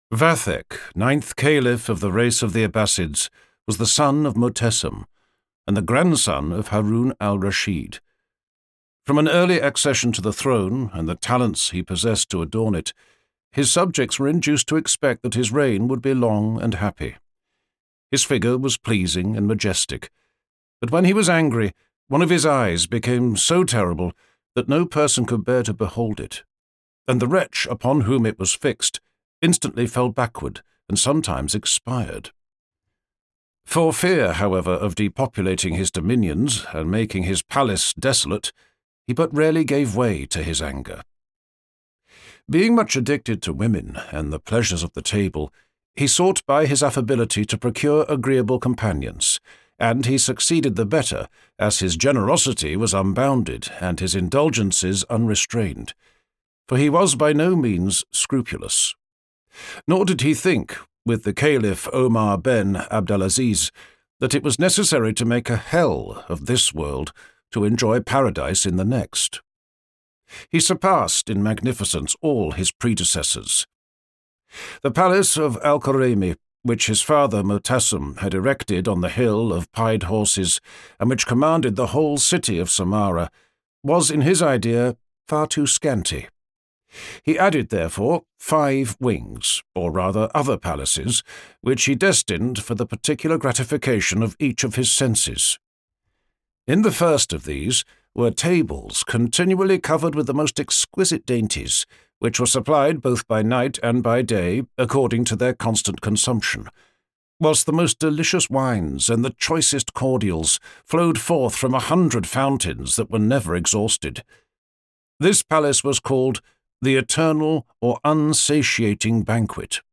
Vathek audiokniha
Ukázka z knihy
vathek-audiokniha